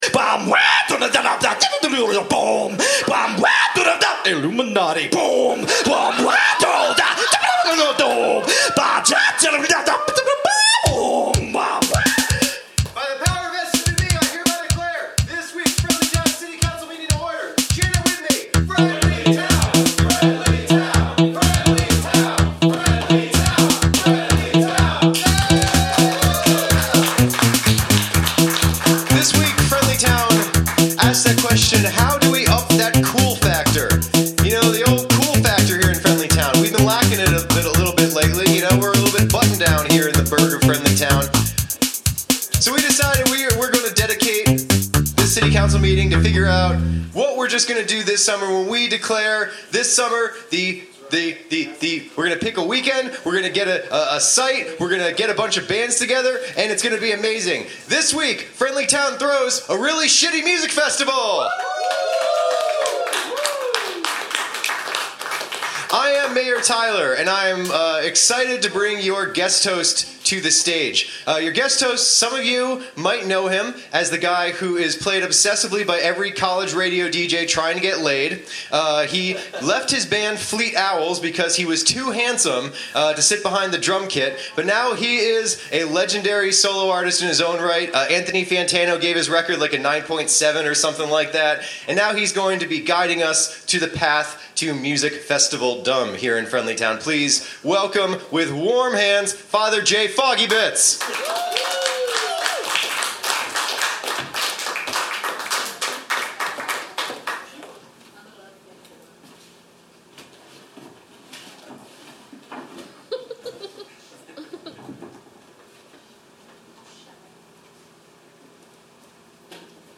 Recorded Live at the Pilot Light February 19, 2017, Knoxville TN.